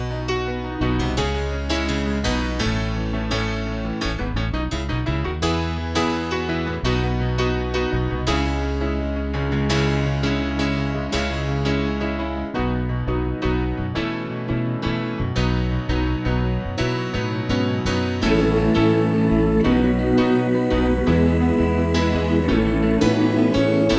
Single Version With Lead Girl Rock 4:15 Buy £1.50